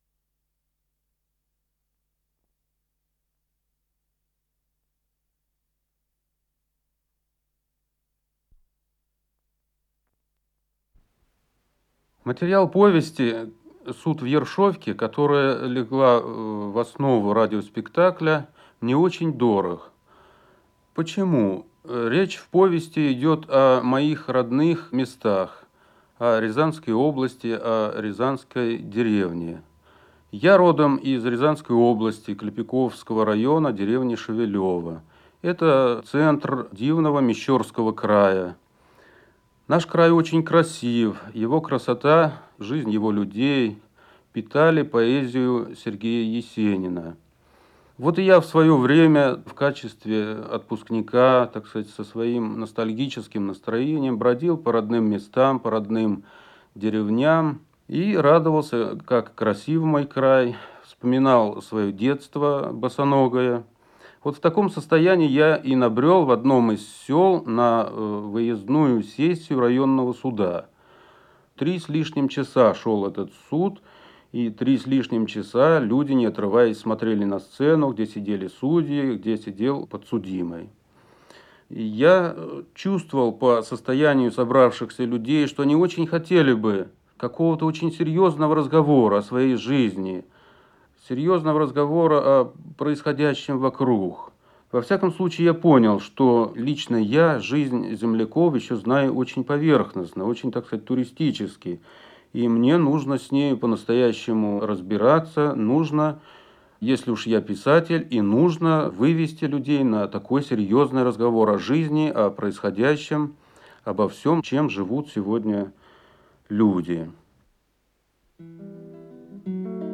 Радиоспектакль по одноименной повести